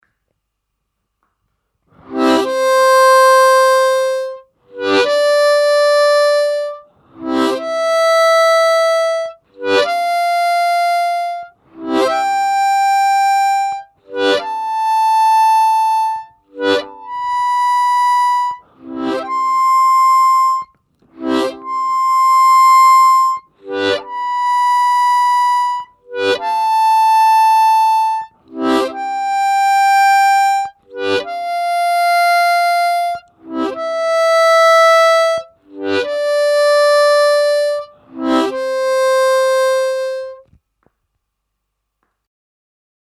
Слайд (глиссандо) и дроп-офф на губной гармошке
Сыграть гамму «до мажор» +4-4+5-5+6-6-7+7 и обратно +7-7-6+6-5+5-4+4, к каждой ноте подъезжая техникой слайд (снизу).